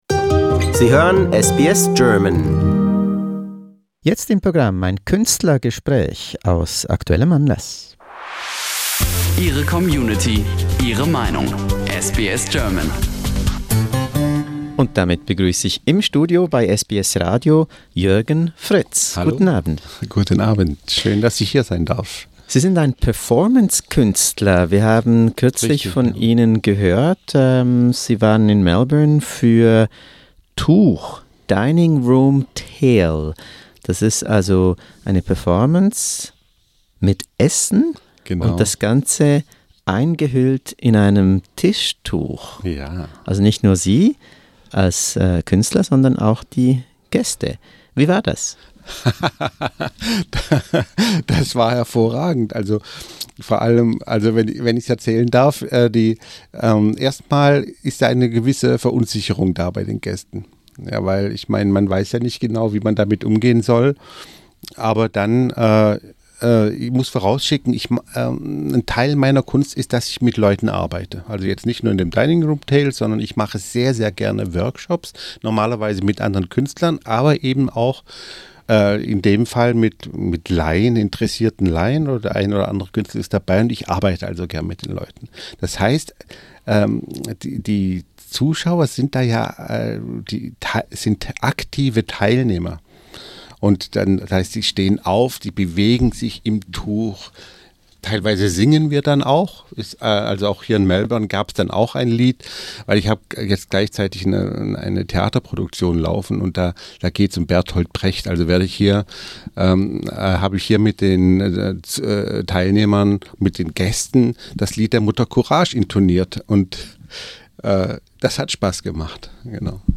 Ein Studiointerview.